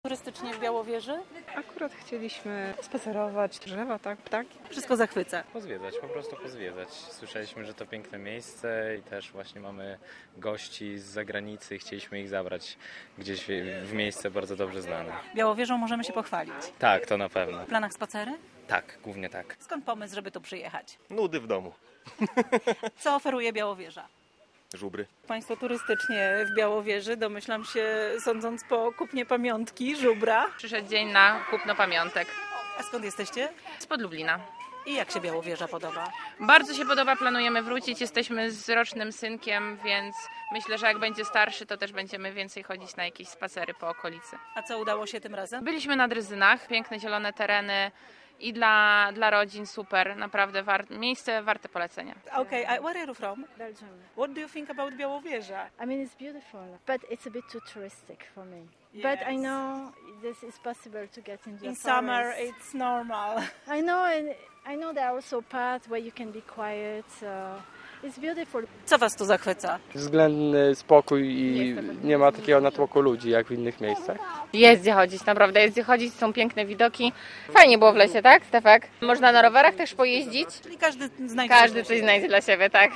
Turyści o Białowieży - relacja